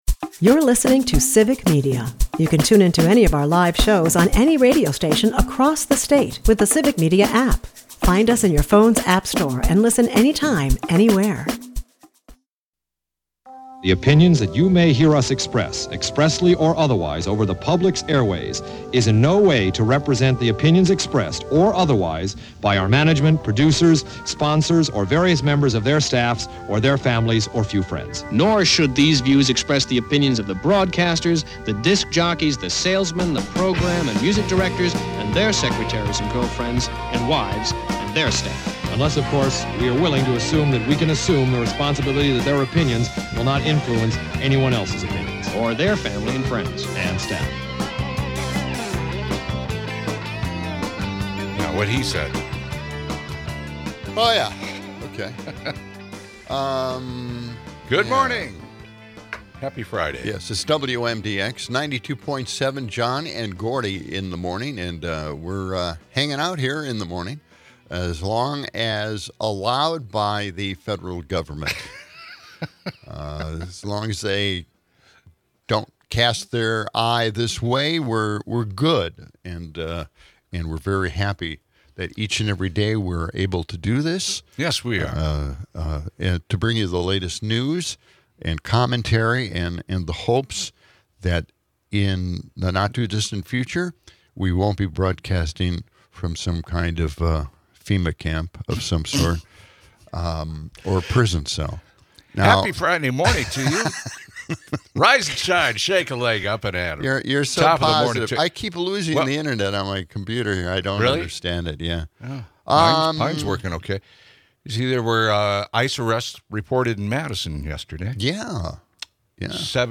The hosts passionately discuss the GOP's assault on healthcare, with insurance costs skyrocketing and Democrats urged to save the ACA. Political commentary highlights the absurdity of the shutdown, and JD Vance's healthcare lies are exposed.